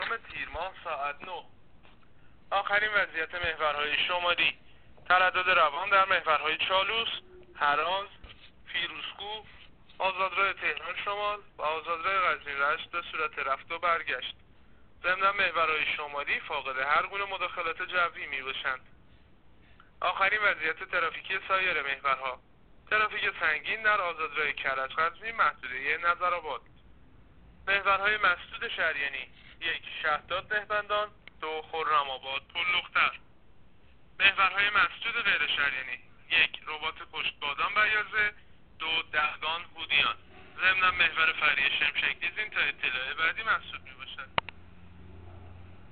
گزارش رادیو اینترنتی از آخرین وضعیت ترافیکی جاده‌ها تا ساعت۹ سی‌ام تیر